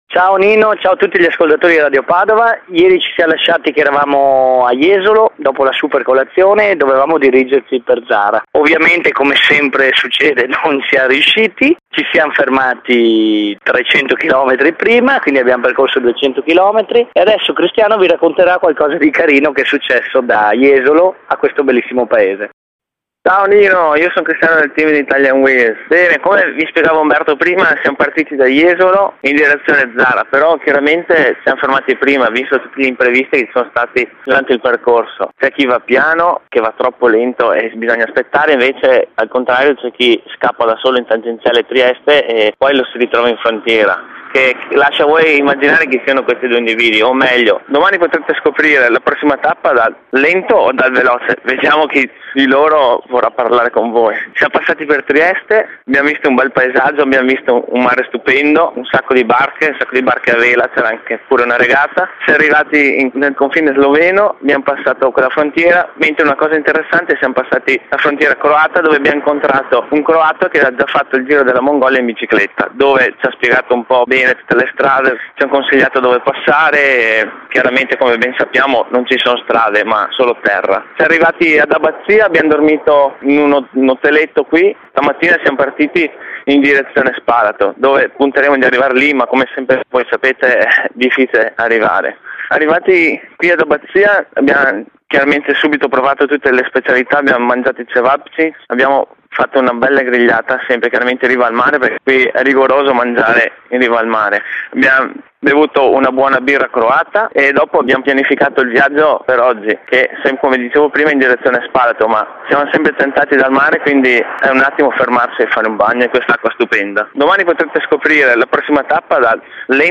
al momento non riesco ad allegare gli MP3 dei collegamenti radiofonici con Radio Padova, ma non disperate... troverò una soluzione...
i potenti mezzi di VOL sono prontamente entrati in soccorso modificando le impostazioni del server ed è ora possibile allegare file MP3 anche di un certo peso! Ecco quindi i file degli interventi successivi:
Buon ascolto dalla viva voce dei protagonisti!